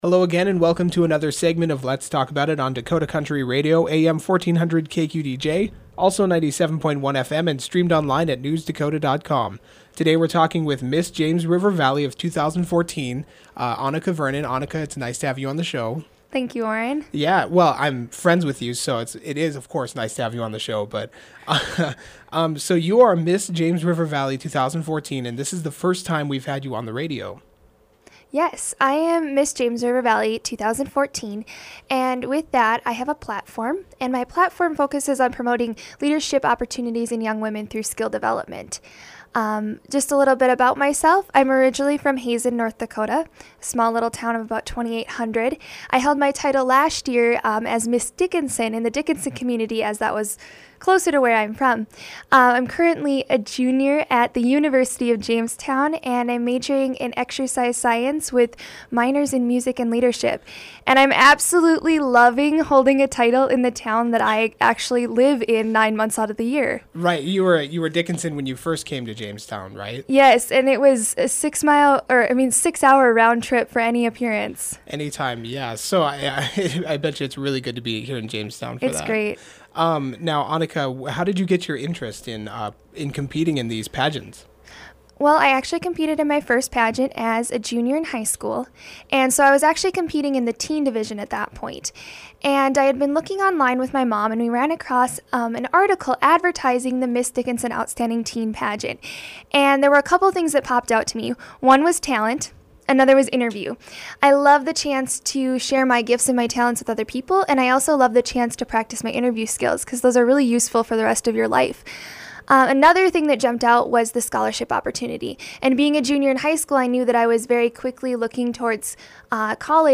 For more information on the event, listen to the full interview below!